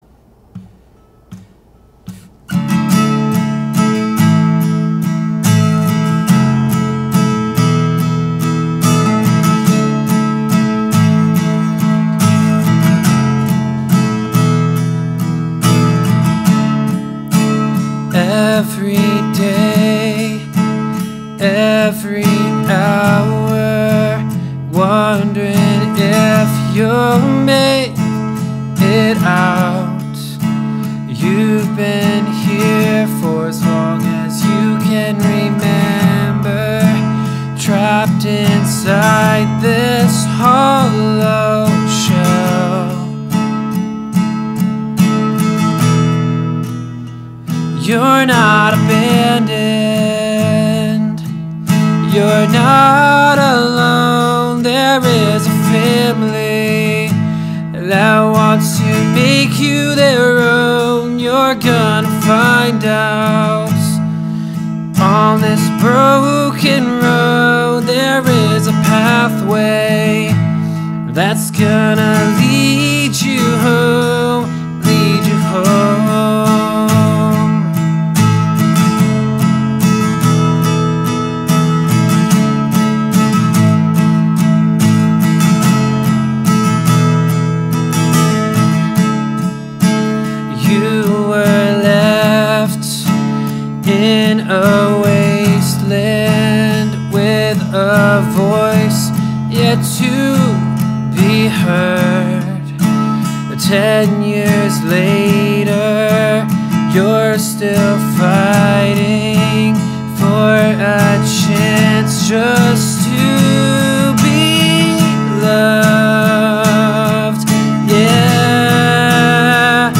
2021 single